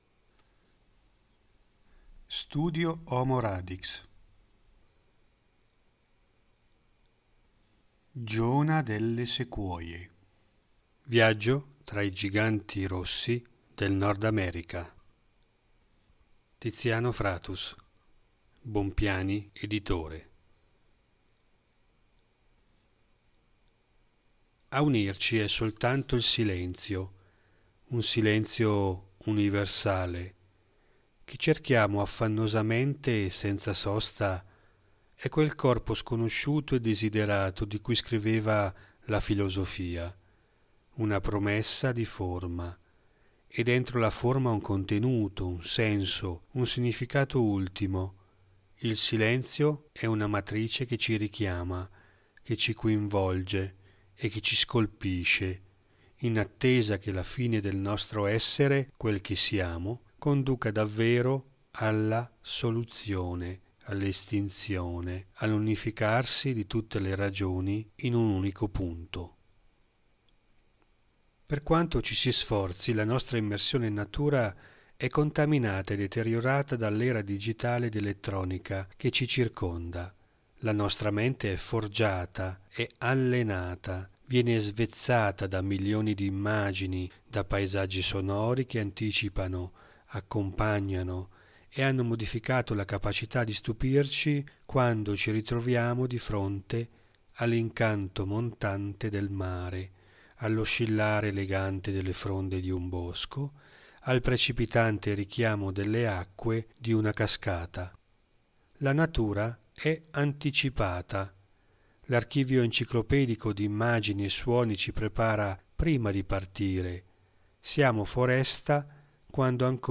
A seguire una selezione di brani e passi letti per voi.